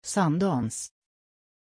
Aussprache von Sundance
pronunciation-sundance-sv.mp3